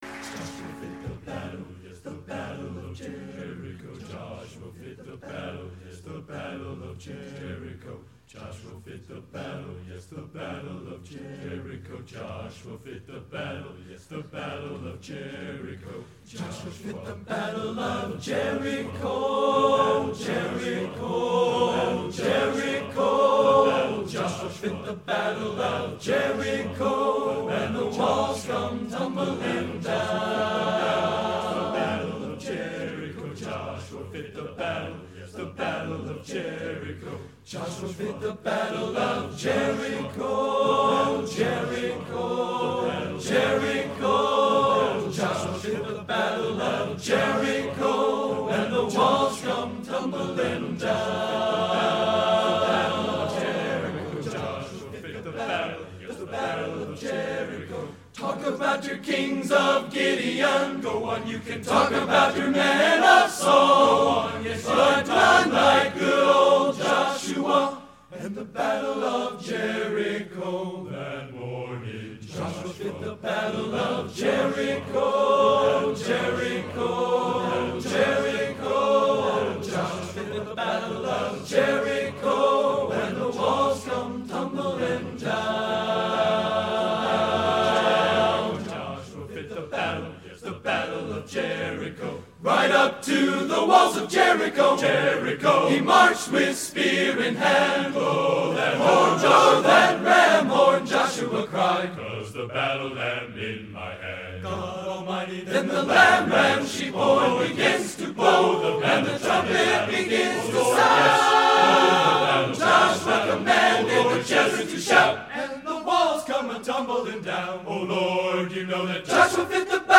Genre: Spiritual | Type:
Nice little speed adjustment at the beginning, there.